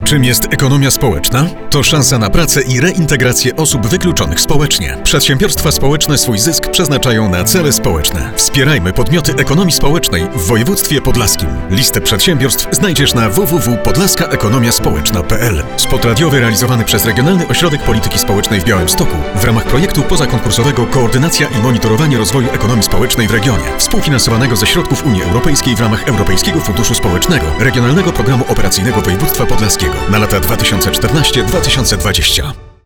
Drugi spot reklamowy: